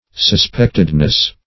-- Sus*pect"ed*ness , n. [1913 Webster]